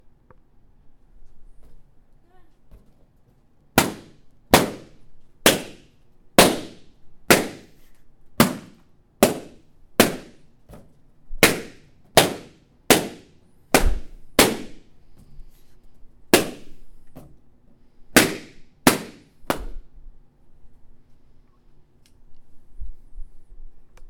poppingballons
ballons crackle pop popping poppingballons sound effect free sound royalty free Sound Effects